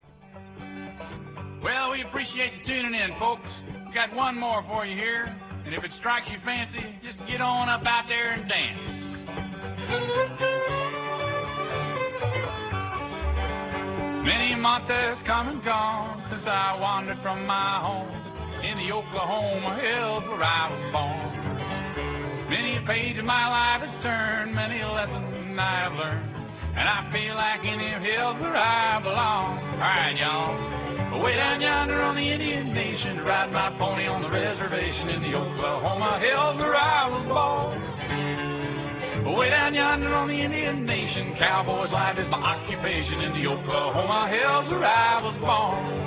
Order original cast recording right from this site: